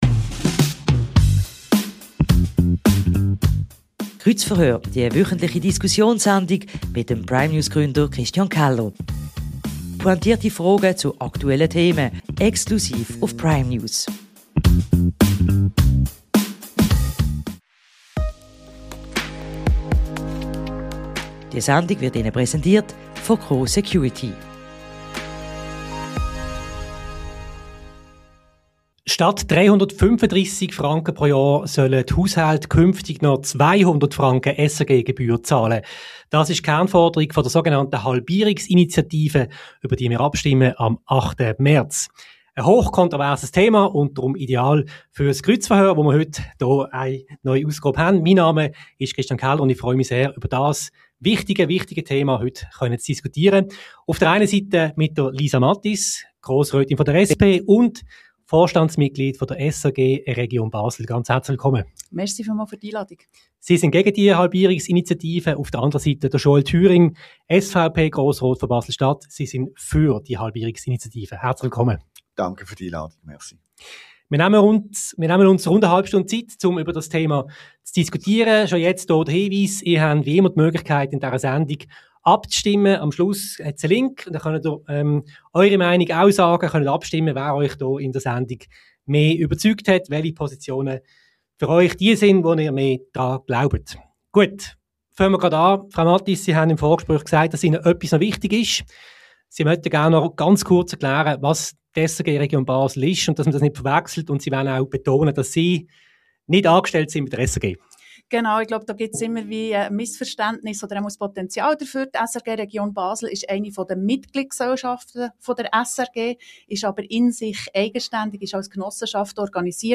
Die Halbierungsinitiative der SRG fordert 200 statt 335 Franken. Es diskutieren Joël Thüring (SVP) und Lisa Mathys (SP).